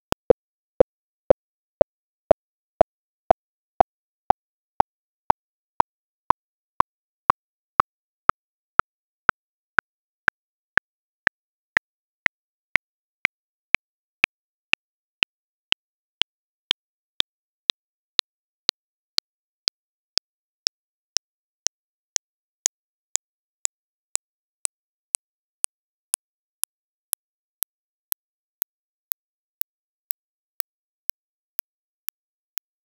Signal5T à partir de 500hz.